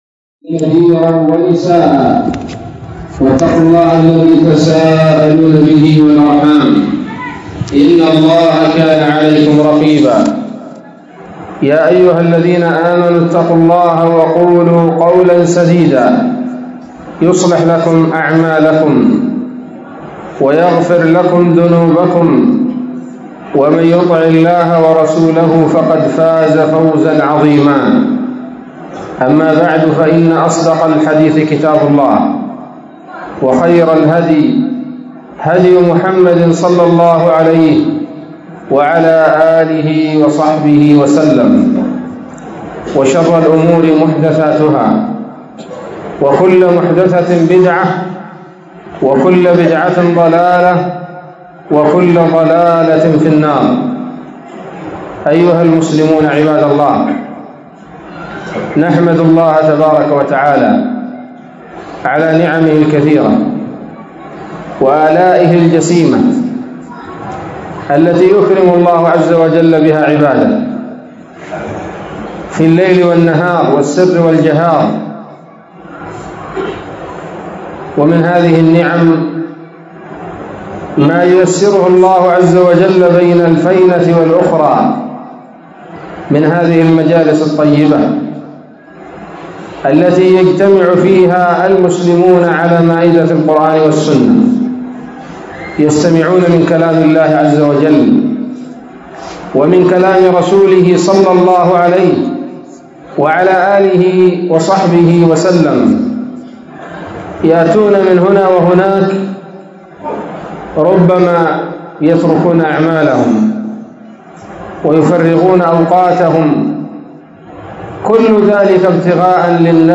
محاضرة بعنوان